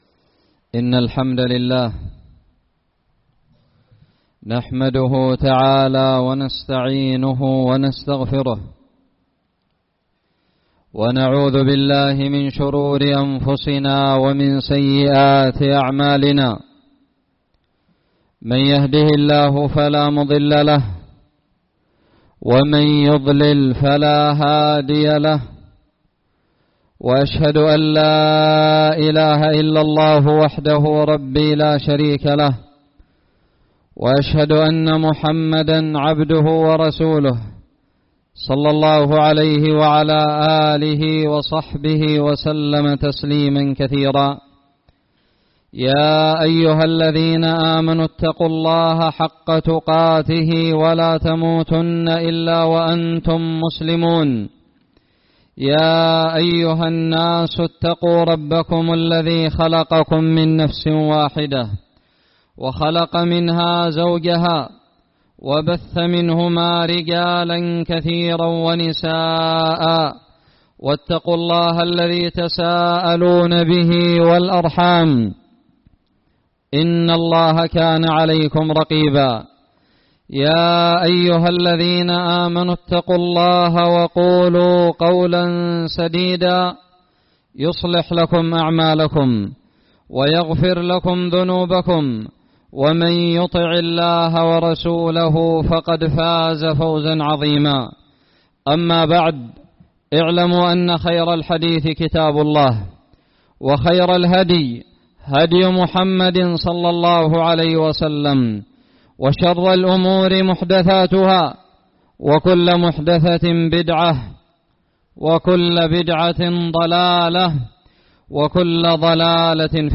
خطب الجمعة
ألقيت – بجعار – أبين في 18 محرم 1440هــ